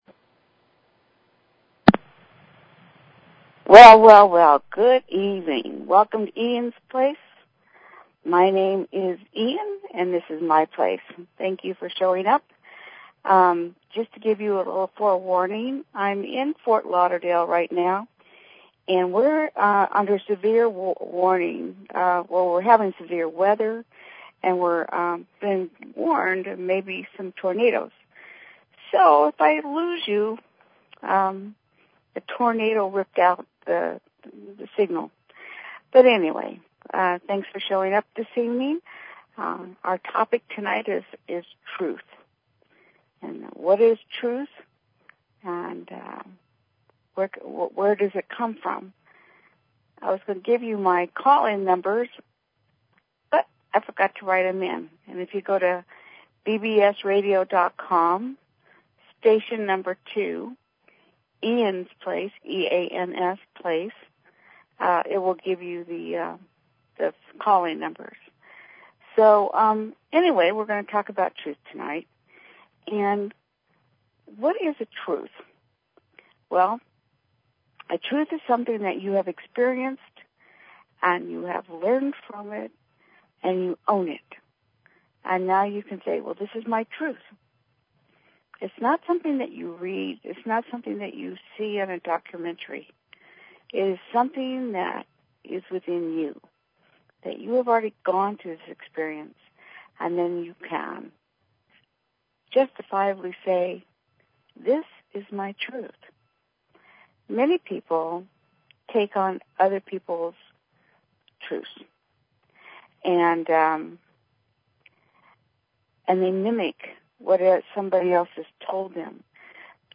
Talk Show Episode, Audio Podcast, Eans_Place and Courtesy of BBS Radio on , show guests , about , categorized as